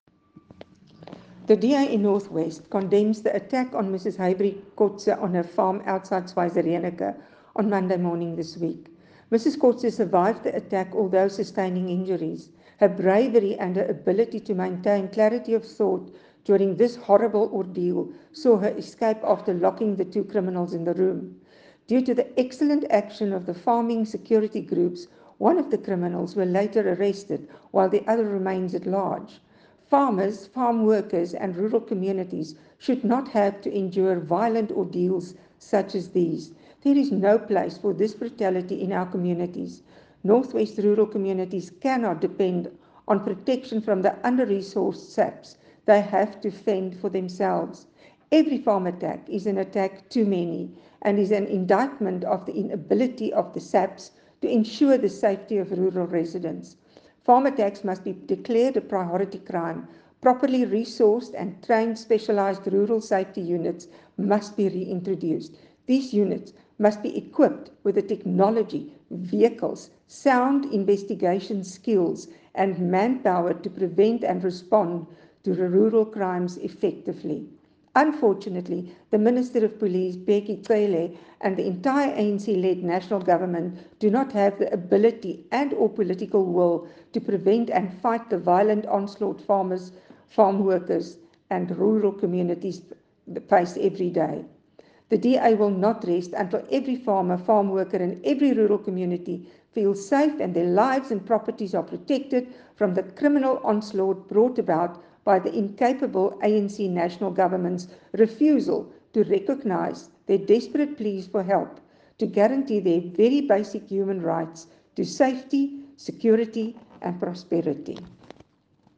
Nota aan Redaksie: Vind asseblief aangehegde klankgrepe in
Carin-Visser-MP-Schweizer-farm-attack-Eng-1.mp3